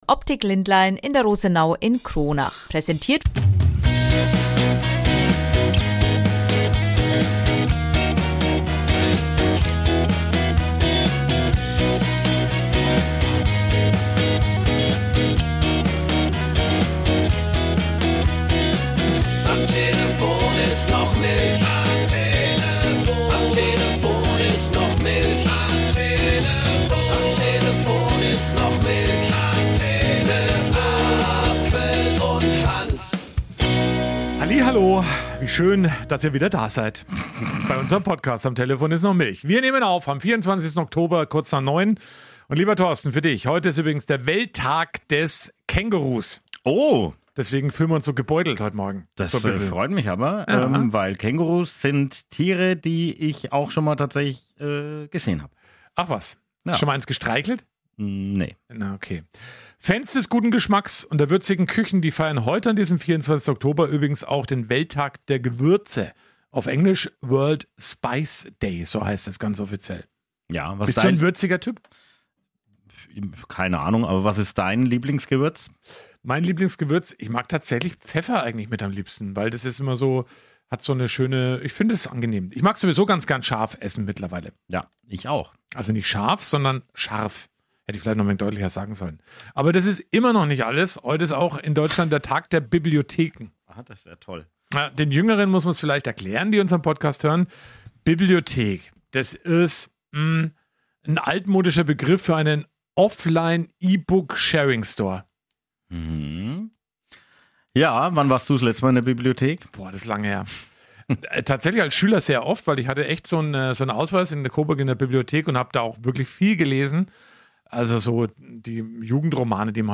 Dazu gibt es viele Berichte und Interviews
mit seinem Küpser Dialekt